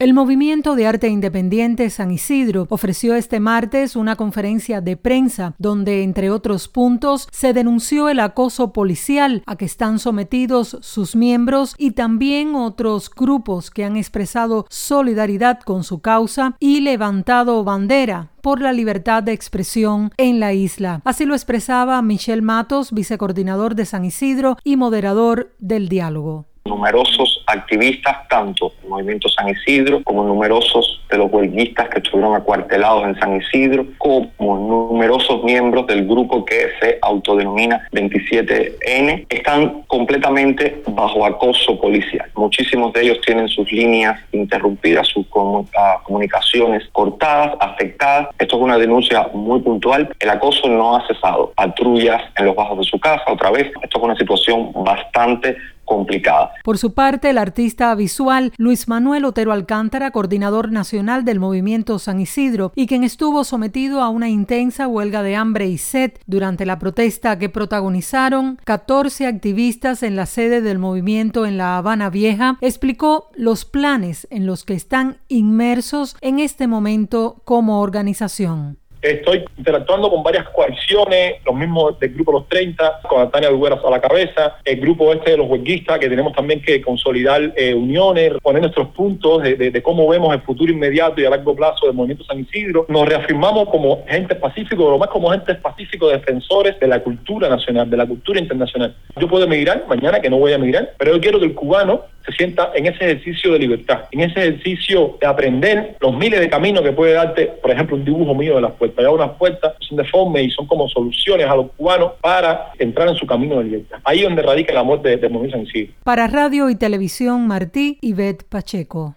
El Movimiento San Isidro ofreció una conferencia donde denunció el acoso policial que viven los artistas e intelectuales que forman parte del grupo disidente y aquellos que forman parte del grupo autodenominado 27N.